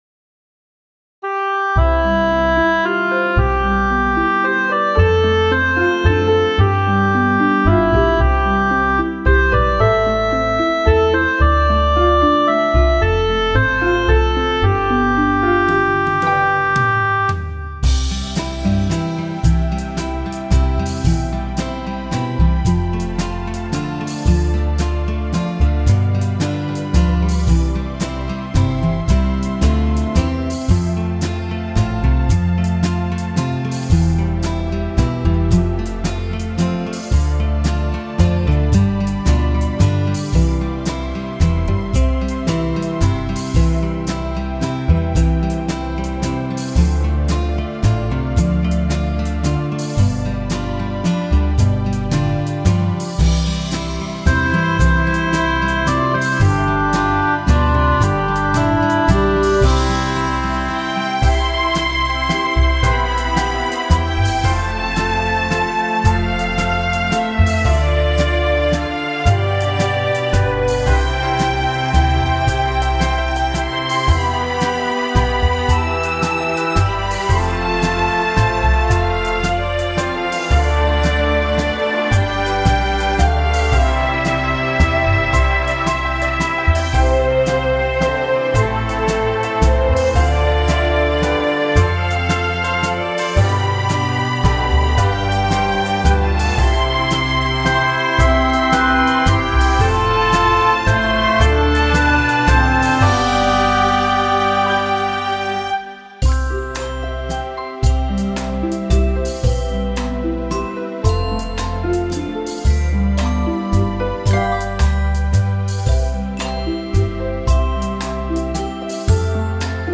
Nghe nhạc hòa tấu karaoke: